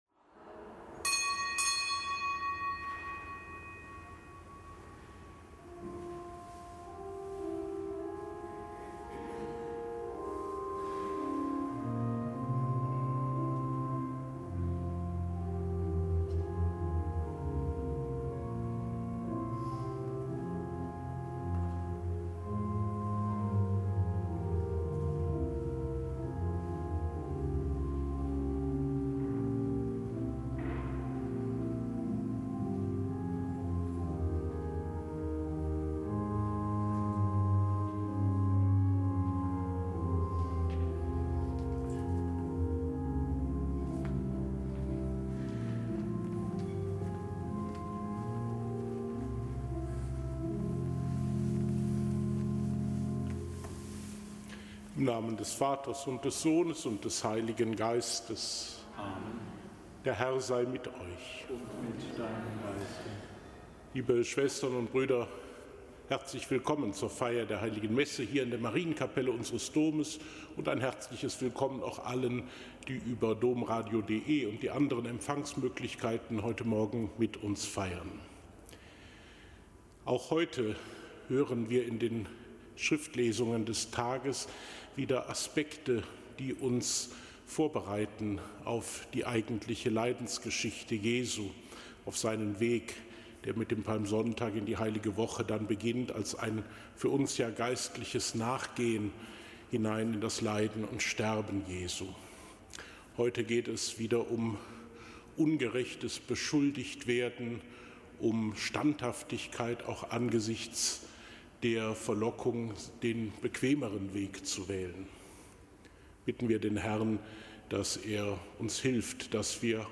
Kapitelsmesse aus dem Kölner Dom am Freitag der fünften Fastenwoche.